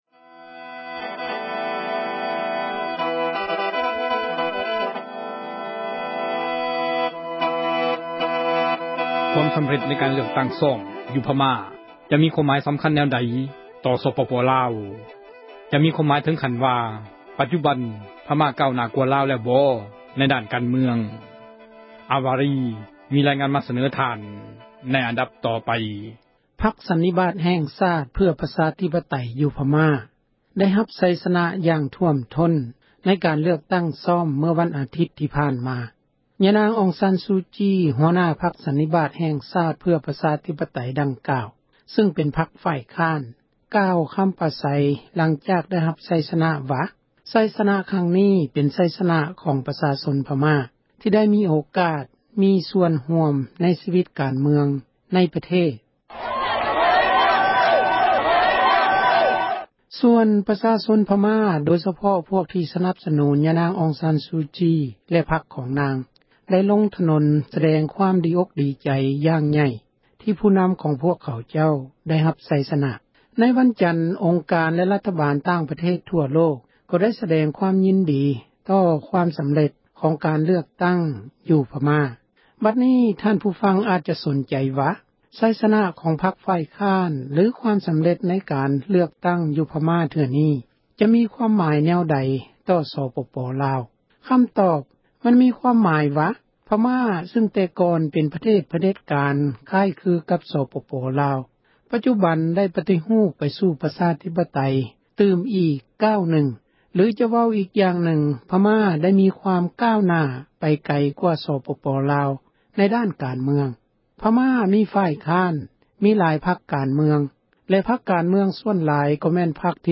ມີຣາຍງານ ໃນອັນດັບ ຕໍ່ໄປ...